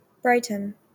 Brighton (/ˈbrtən/
BRY-tən) is a seaside resort in the city of Brighton and Hove, East Sussex, England, 47 miles (76 km) south of London.[1] Archaeological evidence of settlement in the area dates back to the Bronze Age, Roman and Anglo-Saxon periods.